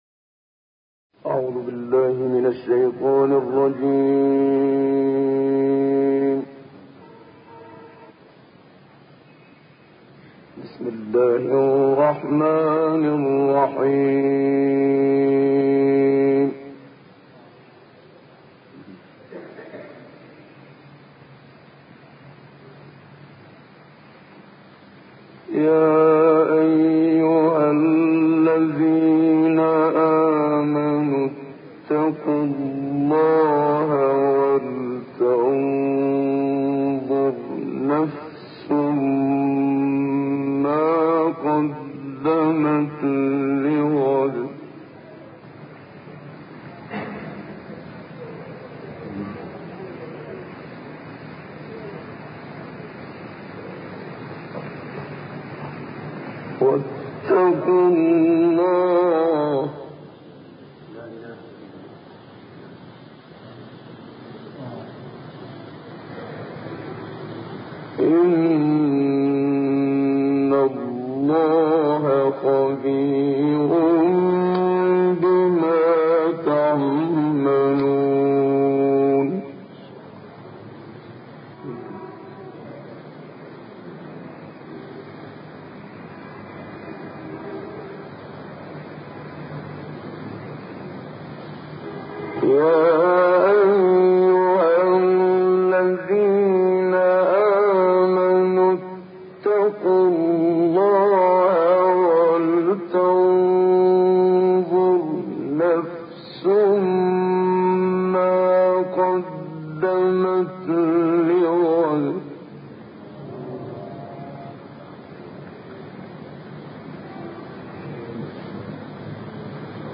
منشاوی حشر 18 تا آخر علق 1 تا 5 در سوریه